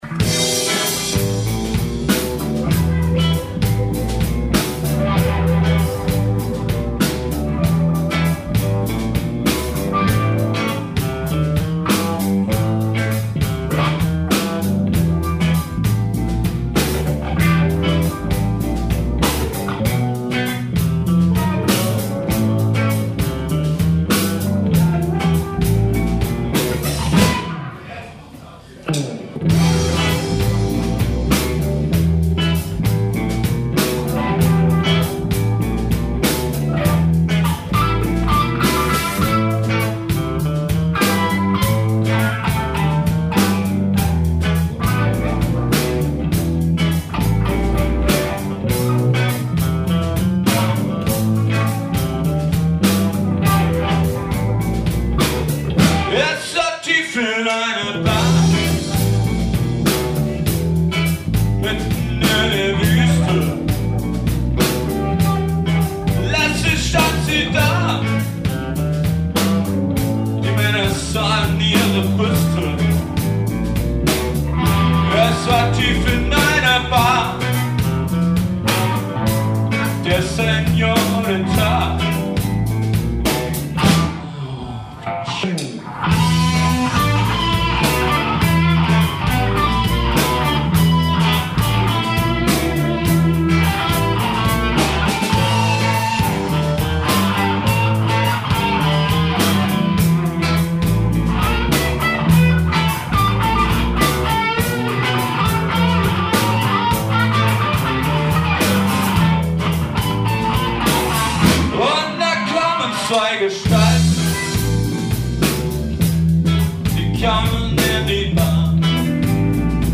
Funk, Soul, Jazz & Electro., Creative